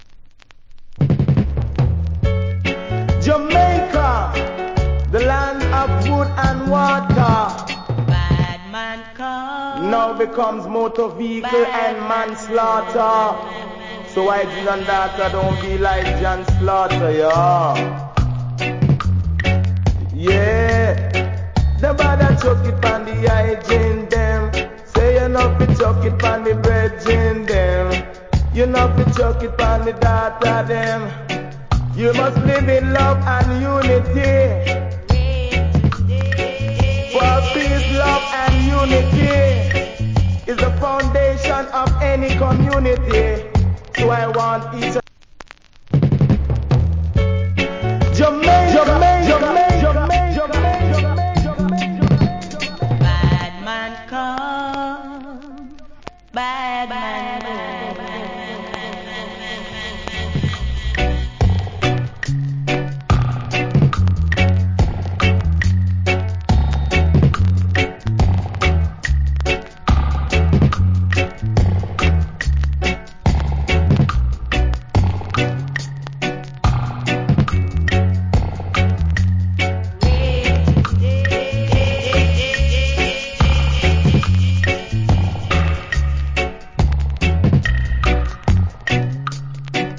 Very Rare DJ.